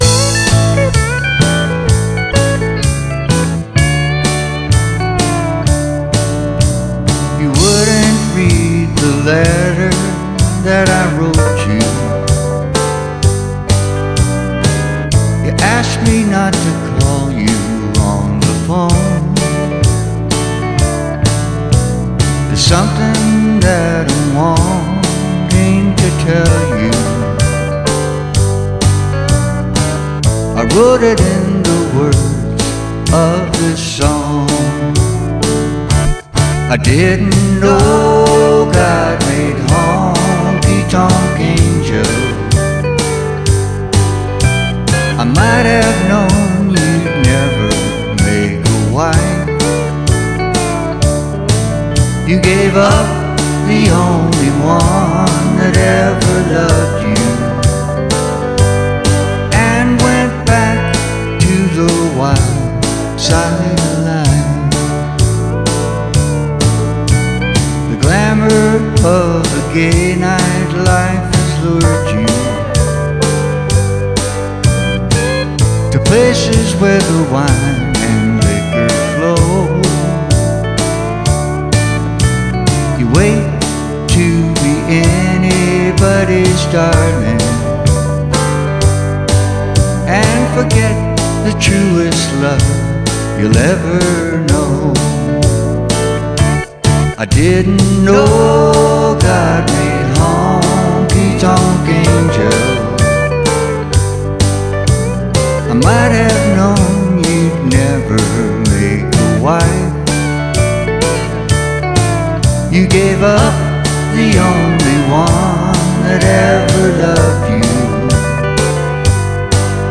vocals, keyboards
MSA pedal steel